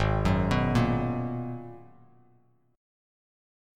G#m9 chord